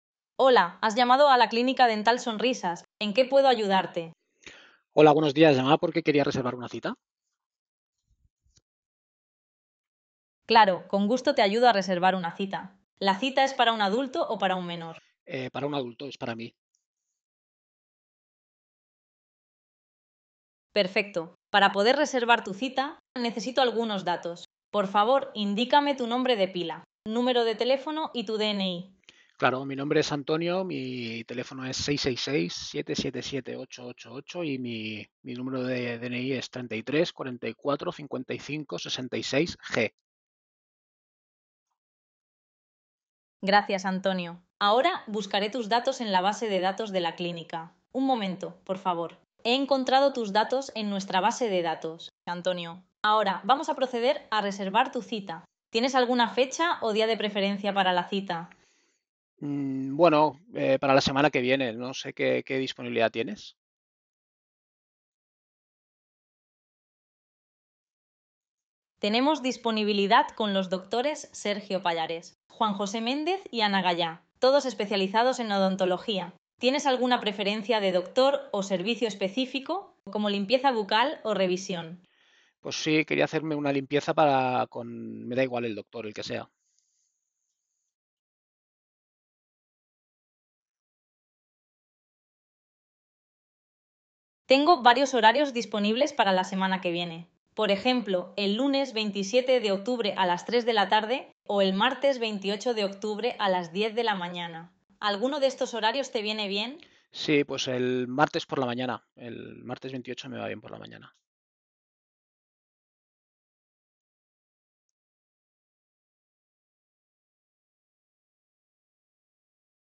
Los agentes de IA de Nubimed atienden llamadas de pacientes con lenguaje natural.